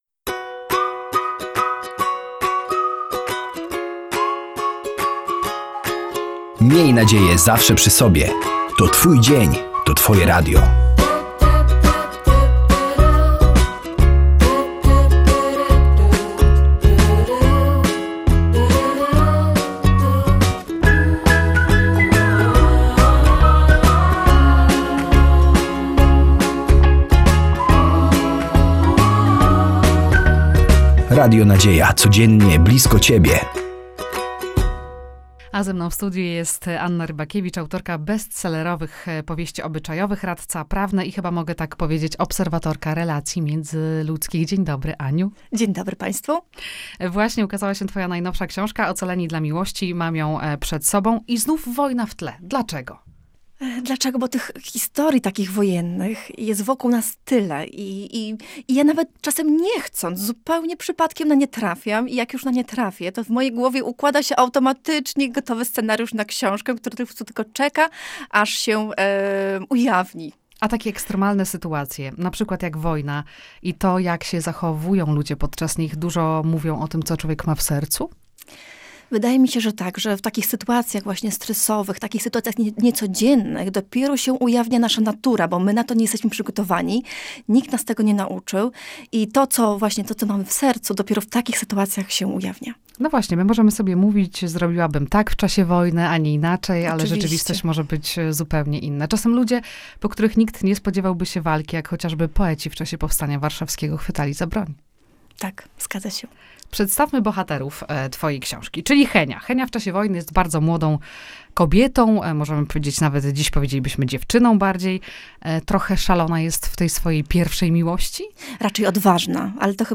„Ocaleni dla miłości” – wywiad